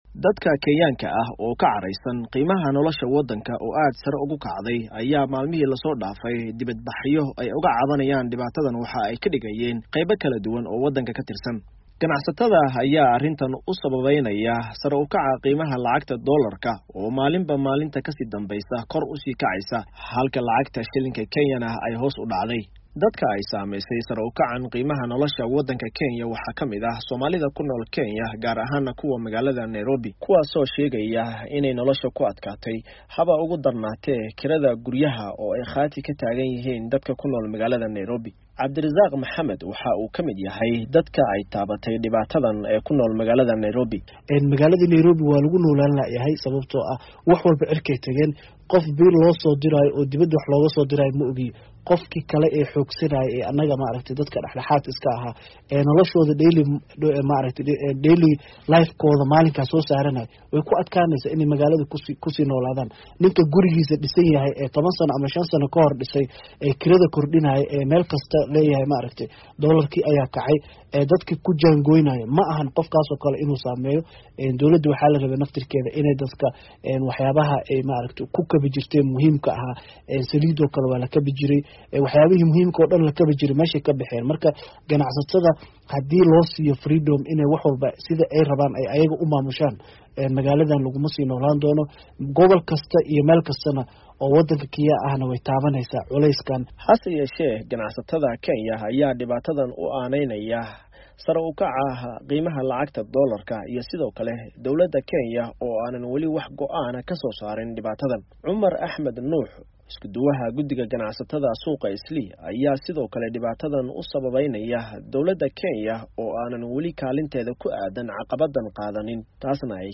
warbixintan ka soo diray magaalada Nairobi.